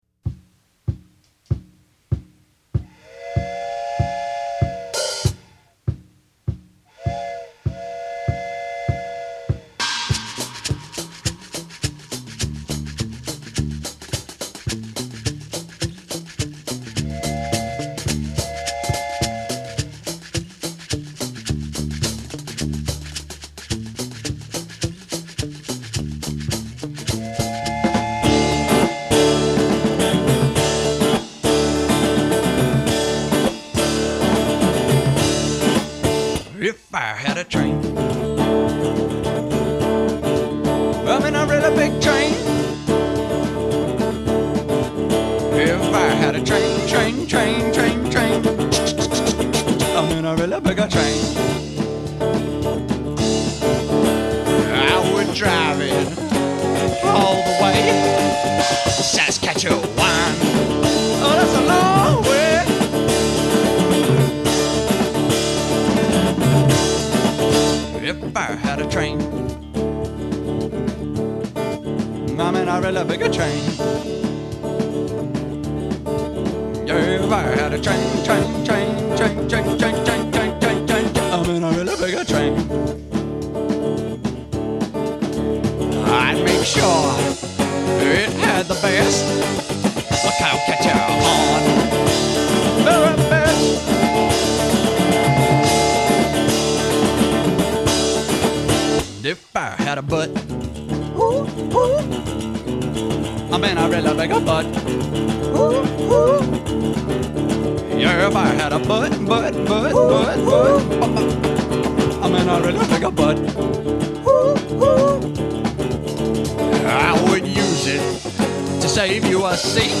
Still the band achieves an engaging groove.